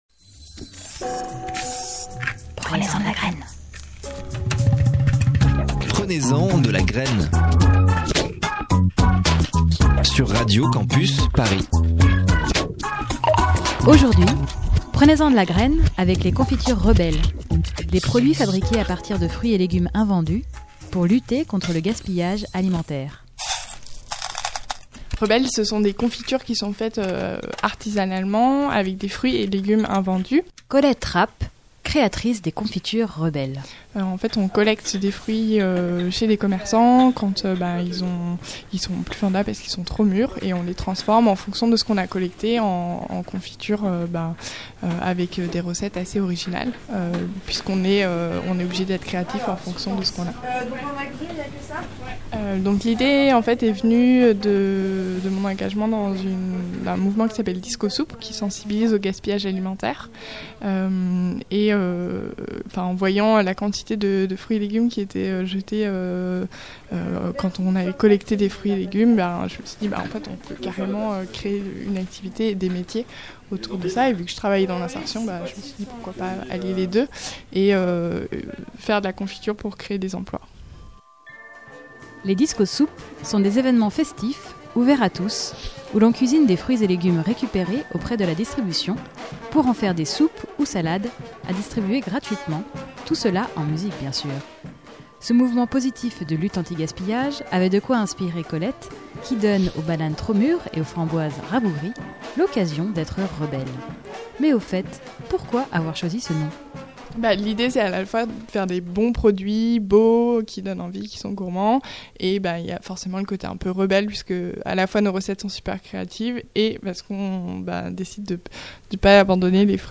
Interview et montage